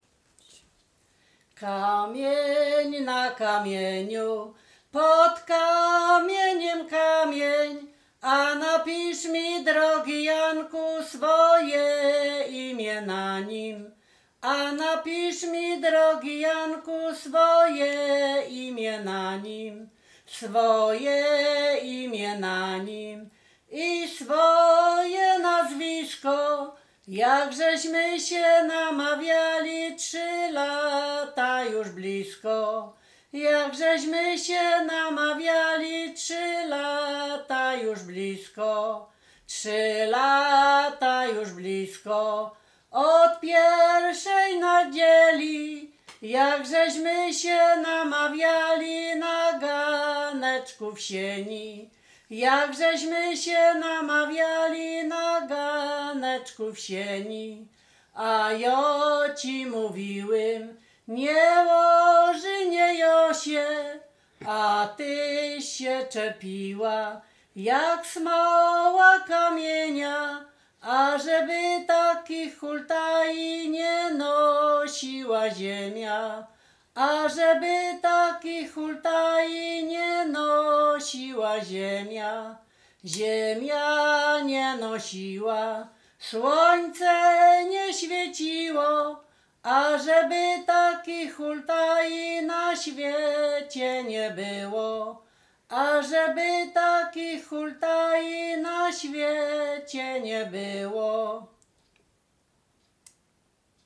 województwo łodzkie, powiat sieradzki, gmina Błaszki, wieś Mroczki Małe
miłosne liryczne